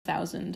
A segment of the "Spoken Text" audio file, specifically the word 'thousand'. No further audio effects were added. This sound is correlated with the letter "o" on the computer keyboard.